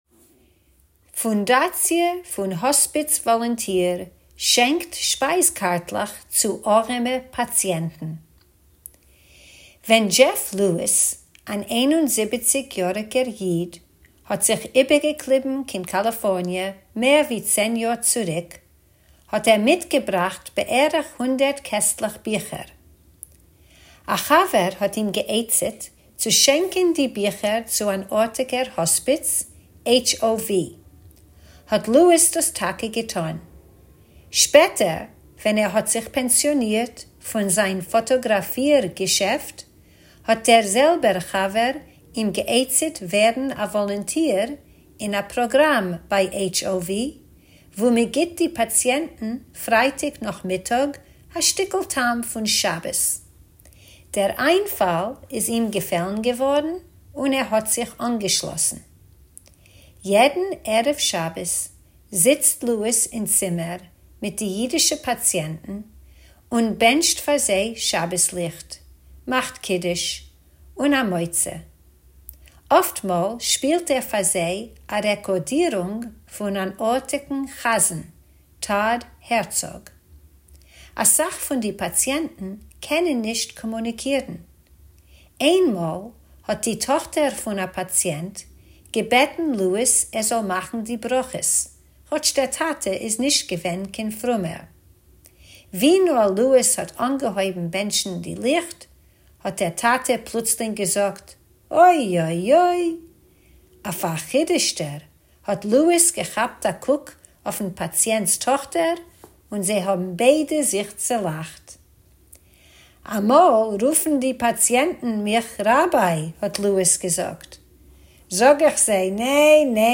Tidbits is a Forverts feature of easy news briefs in Yiddish that you can listen to or read, or both!